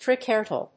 triquetral.mp3